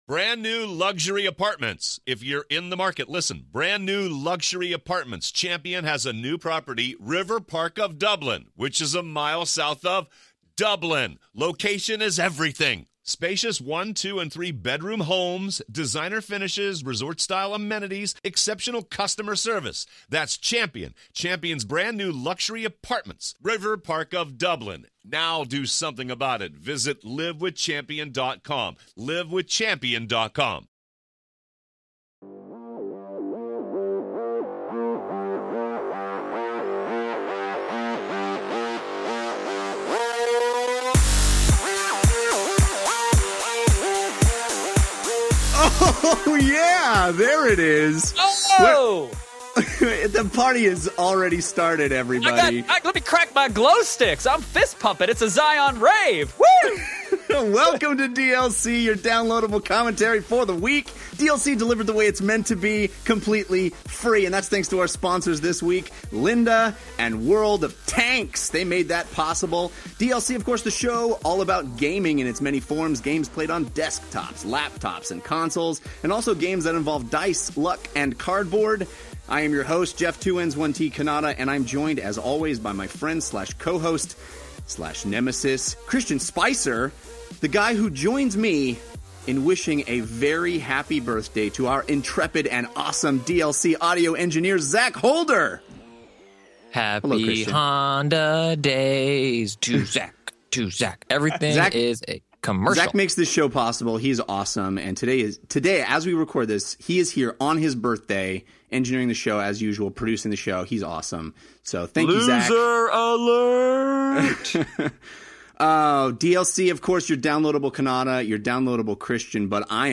All that, plus YOUR phone calls!